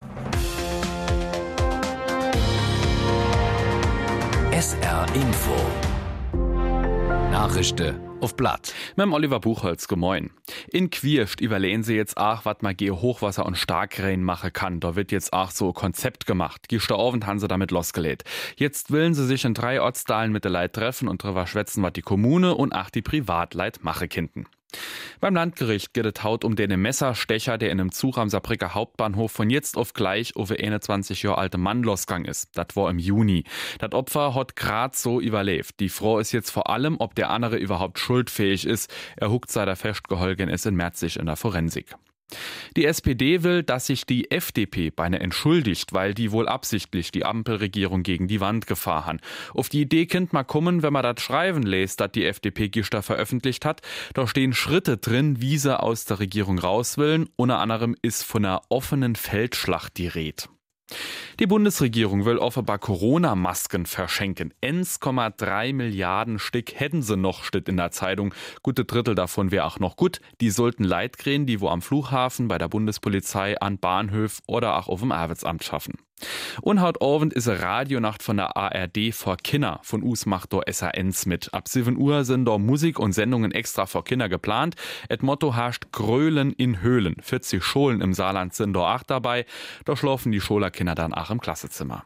Von Montag bis Freitag gibt es bei SR 3 Saarlandwelle täglich um 08:30 Uhr Schlagzeilen in Mundart. Die "Nachrischde uff platt" werden mal in moselfränkischer, mal in rheinfränkischer Mundart präsentiert. Von Rappweiler bis Dudweiler, von Dillingen bis Püttlingen setzt sich das Team aus Sprechern ganz verschiedener Mundartfärbungen zusammen